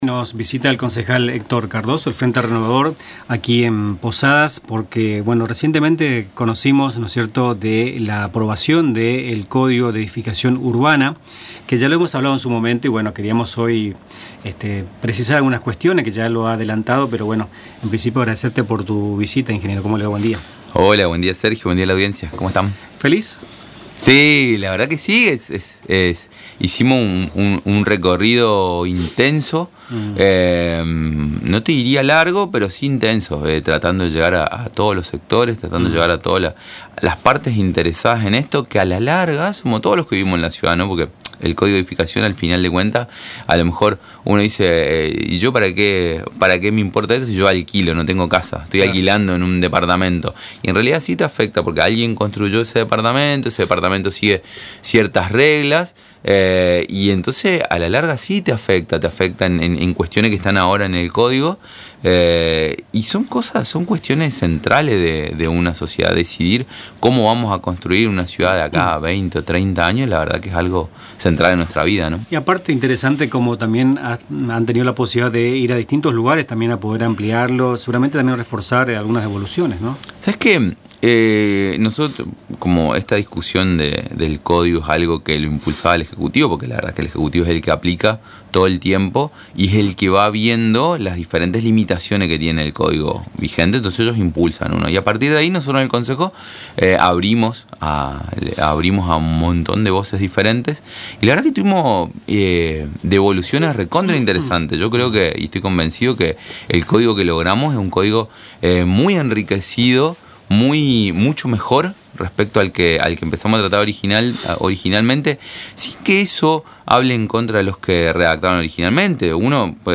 El Honorable Concejo Deliberante (HCD) de Posadas aprobó un renovado Código de Edificación Urbana, que moderniza la normativa vigente tras más de 40 años y define cómo se construirá la ciudad en las próximas décadas. En diálogo con Nuestras Mañanas, el concejal Héctor Cardozo, del Frente Renovador, explicó que la medida busca agilizar trámites, promover la accesibilidad y garantizar mayor transparencia y seguridad en el desarrollo urbano de la capital misionera.